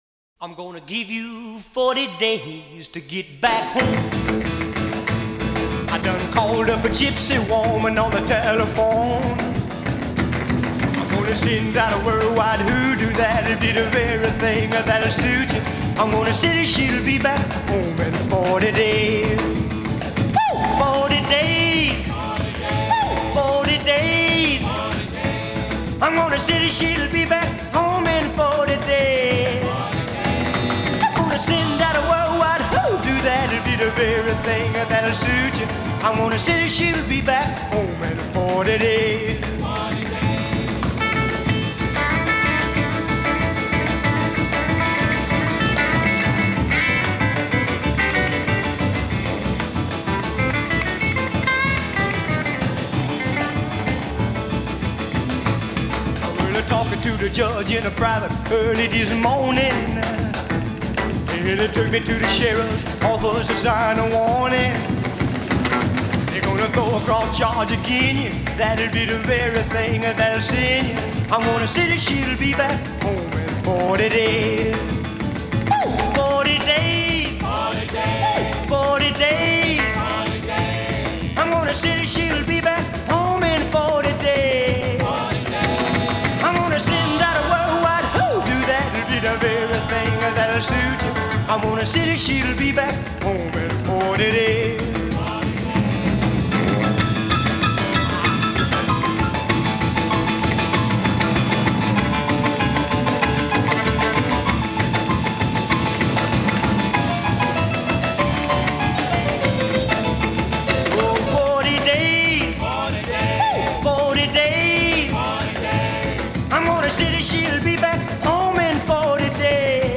Canadian based rocker